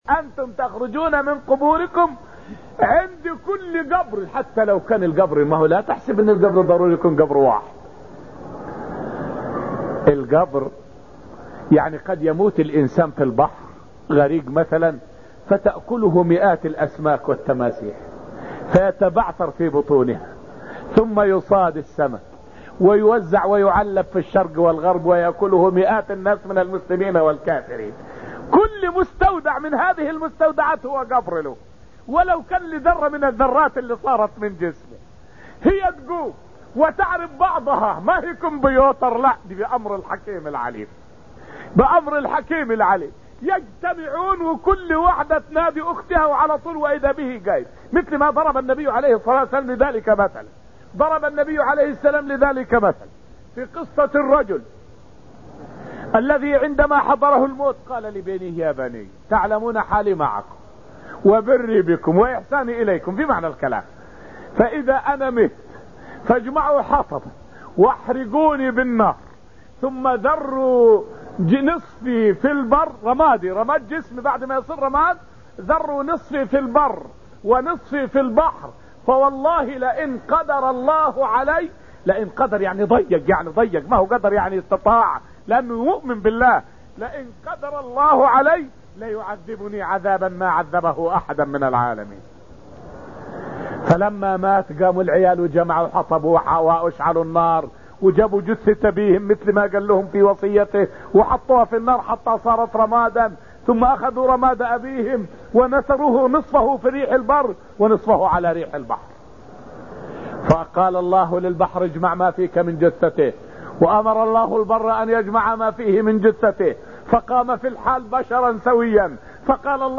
فائدة من الدرس الرابع من دروس تفسير سورة القمر والتي ألقيت في المسجد النبوي الشريف حول فصاحة النبي عليه الصلاة والسلام.